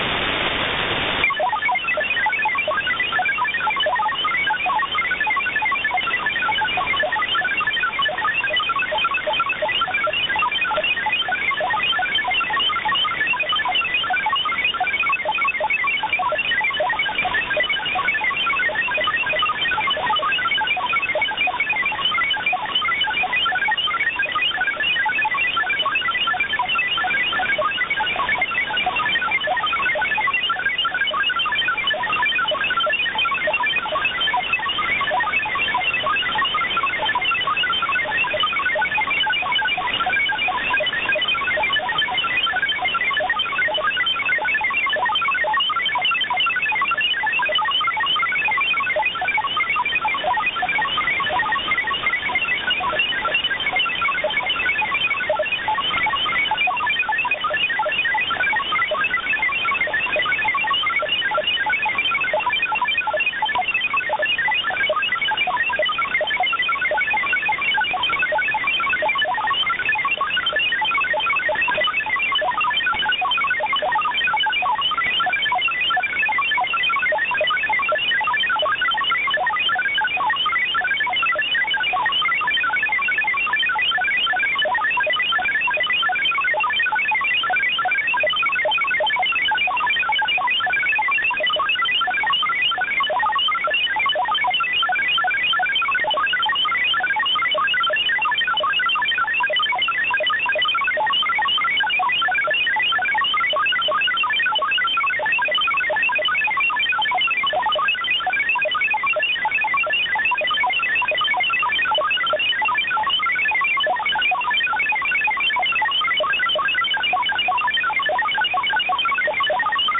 MFSKMultiple Frequency Shift-Keying-21-13 signal
CIS_MFSK_21-13_full.ogg